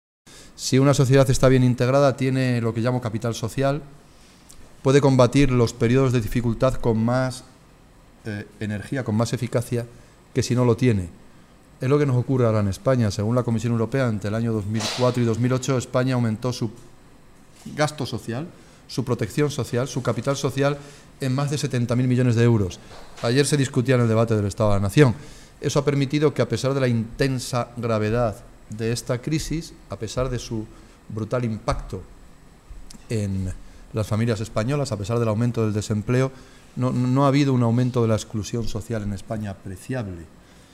Caldera que compareció en rueda de prensa antes de presentar en Toledo su libro “Tiempo para la Igualdad”, señaló que se han tenido que tomar medidas de ajuste muy importantes en nuestro país, “pero ahora que las cosas han empezado a serenarse, hay que actuar con contundencia a favor de los que más han pagado la crisis y me alegro mucho que mañana se apruebe esta medida porque la culpa de que muchos ciudadanos no puedan pagar su hipoteca no la tienen ellos sino la tremenda crisis que ellos no han provocado”.